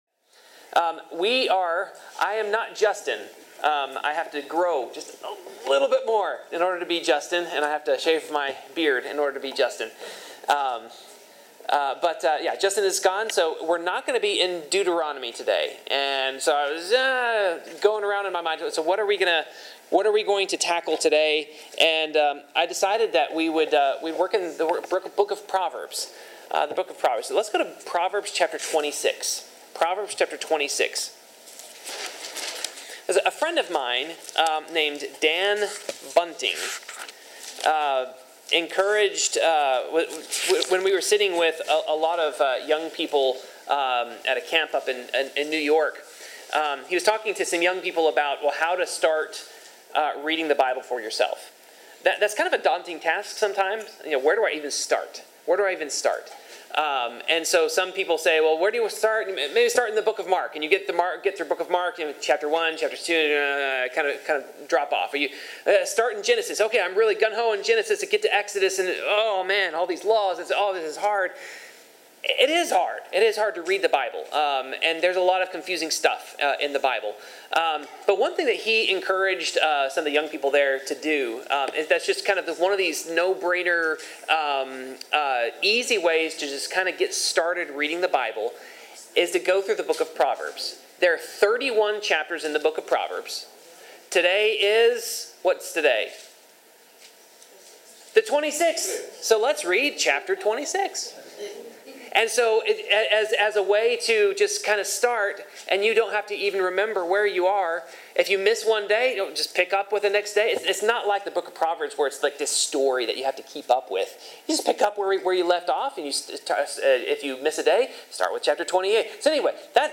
Bible class: Proverbs 26
Service Type: Bible Class Topics: Dealing with fools , Holiness , Power of the Tongue , Sloth , Wisdom